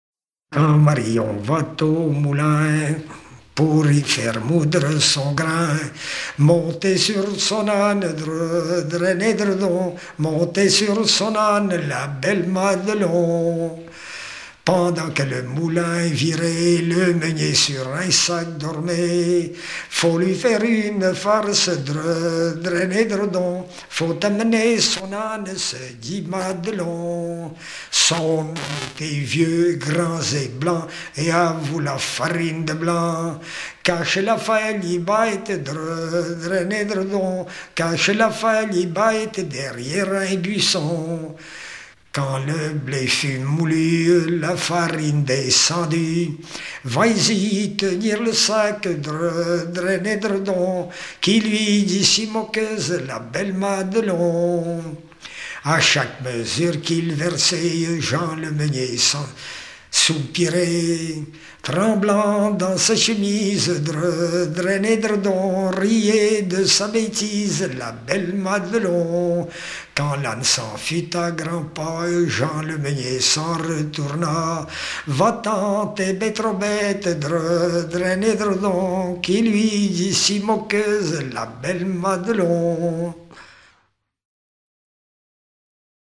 Localisation Saint-Hilaire-de-Riez
Genre laisse
Pièce musicale éditée